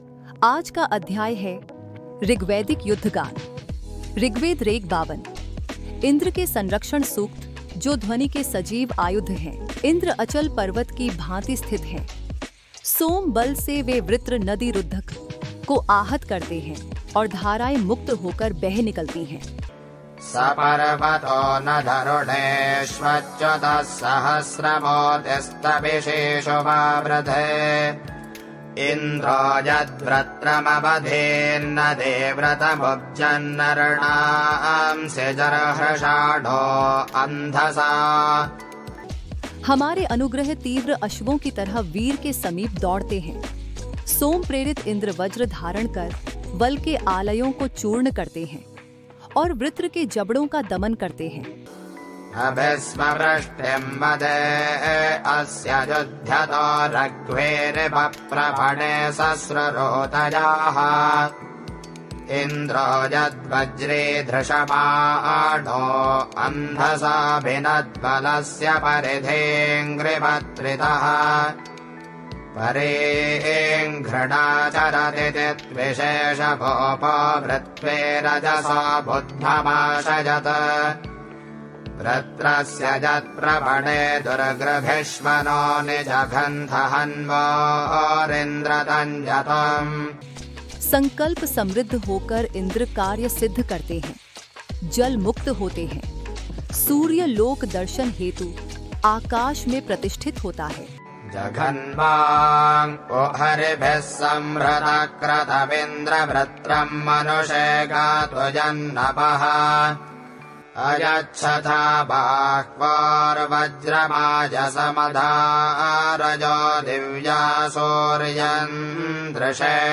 Shield of Sound: Bringing Rigvedic Battle Chants into Daily Life
Protection-Mantras-12-Audio.mp3